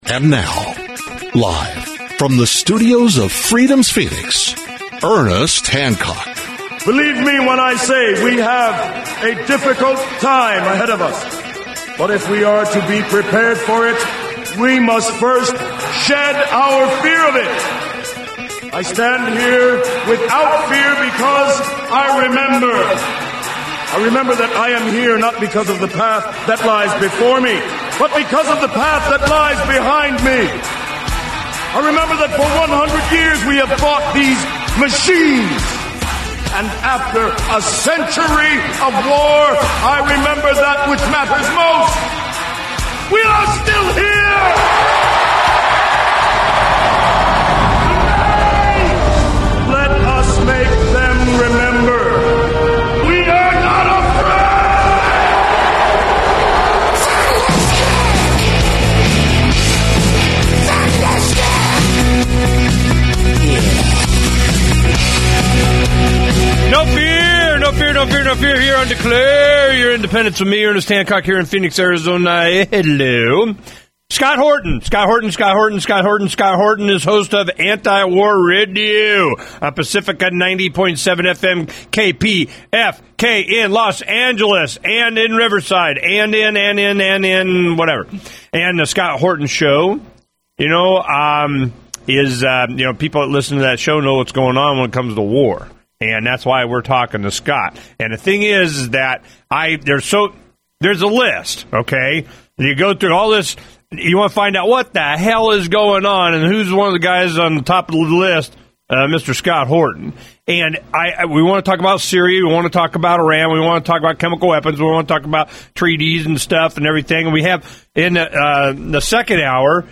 Radio/TV